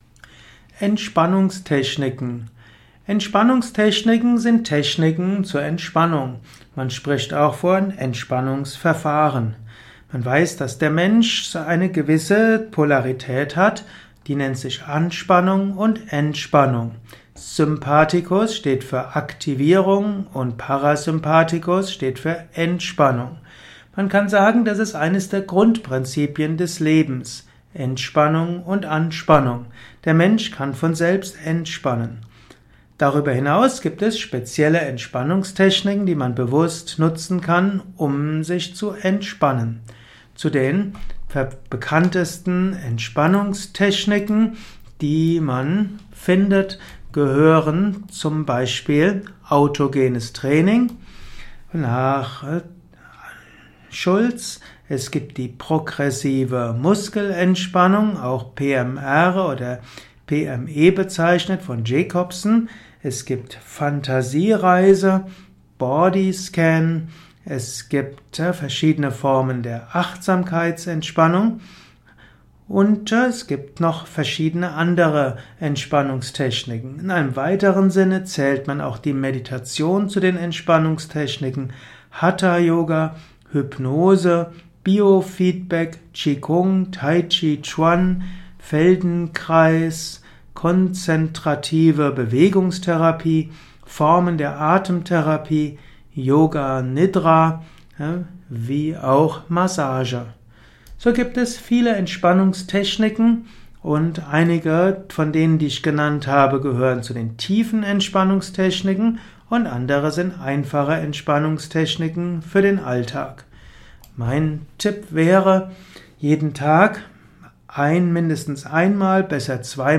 Vortragsaudio rund um das Thema Entspannungstechniken. Erfahre einiges zum Thema Entspannungstechniken in diesem kurzen Improvisations-Vortrag.
Der Yogalehrer und interpretiert das Wort bzw. den Ausdruck Entspannungstechniken vom spirituellen Gesichtspunkt her.